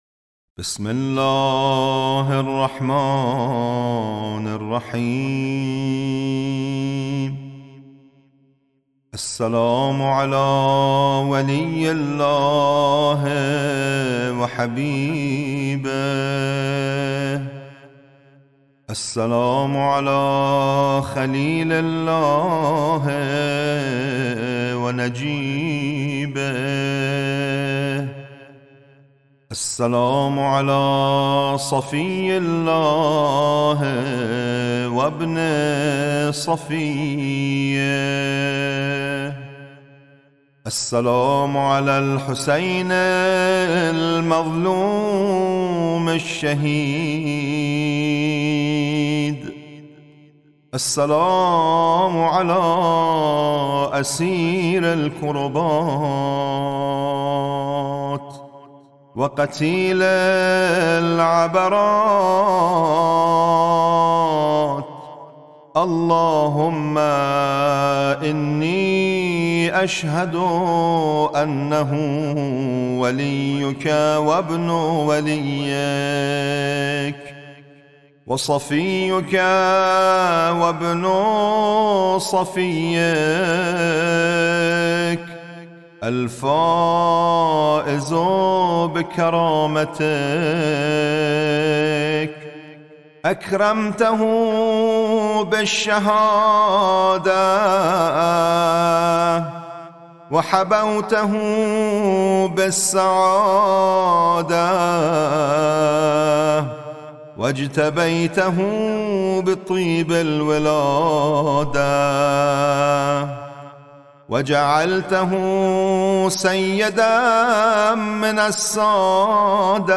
قرائت زیارت اربعین